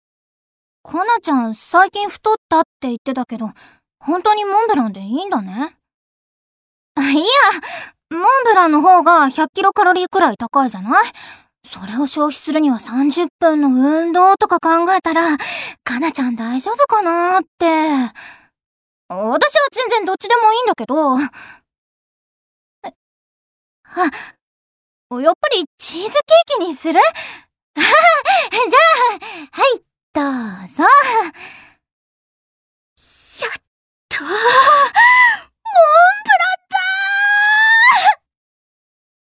フブキ　クロックフォードの声優は誰？（ボイスサンプル付き）
CV：大西沙織
ボイス